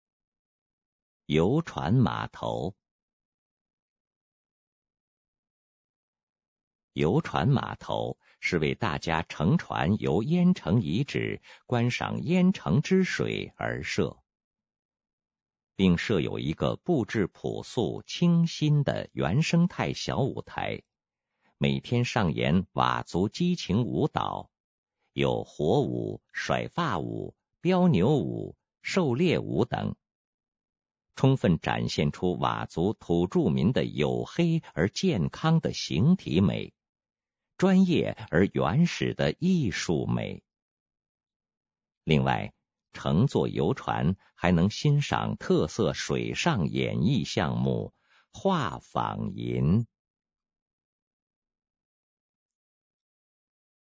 语音导览